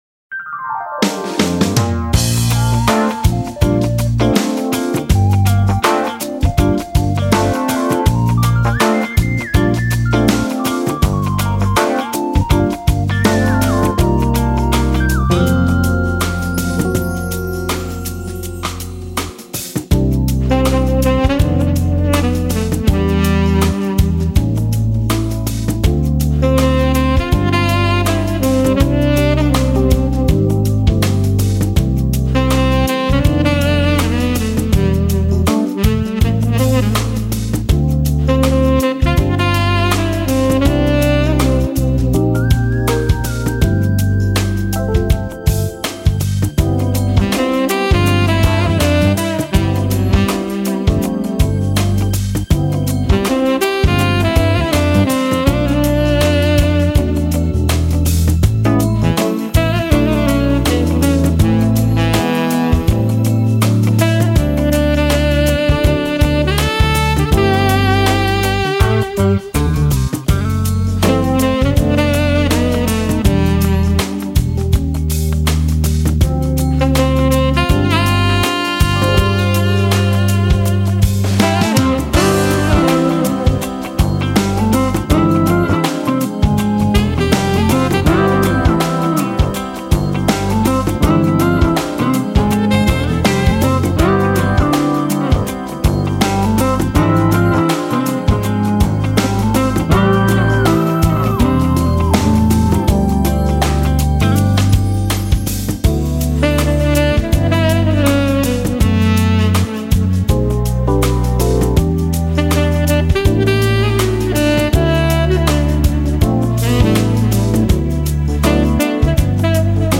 柔情薩克斯風
他..給我們貼心的撫慰 他..給我們萬般的沉醉 他..讓薩克斯風音化為一種酣甜的幸福氣味